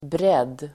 Uttal: [bred:]